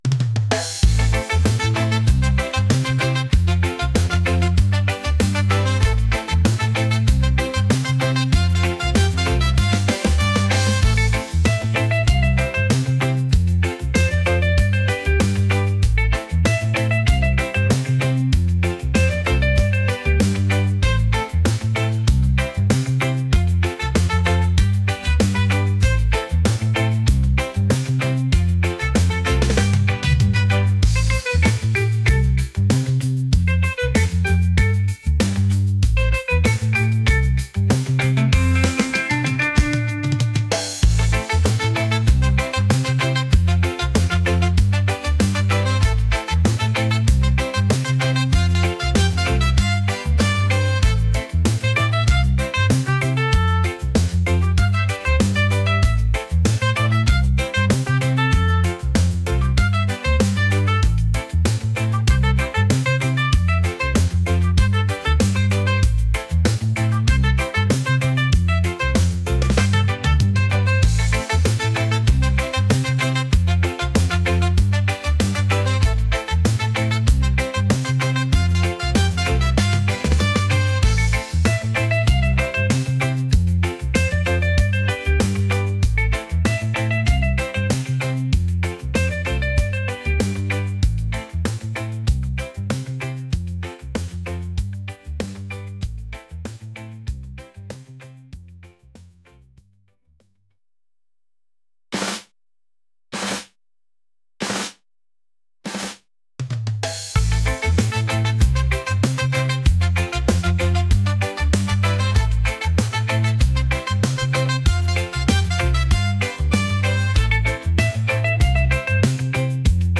upbeat | energetic | reggae